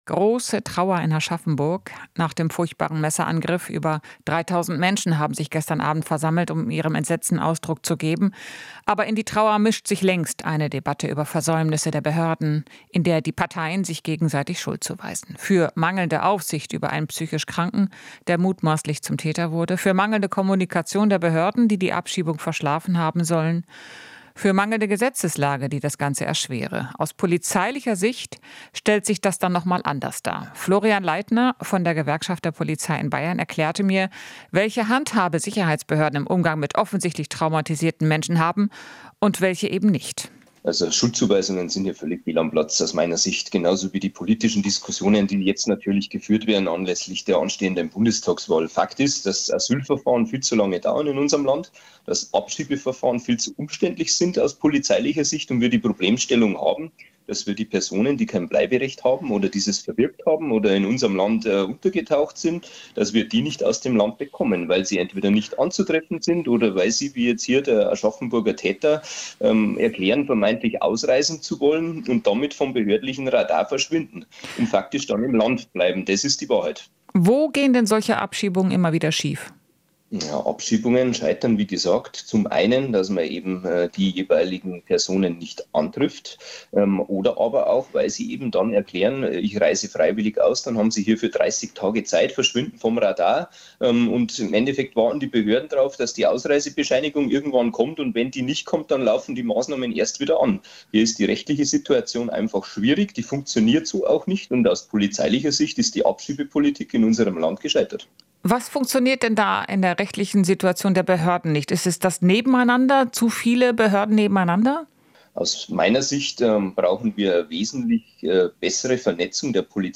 Interview - Polizeigewerkschaft: "Schuldzuweisungen sind fehl am Platz"